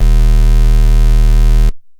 C#_07_Bass_01_SP.wav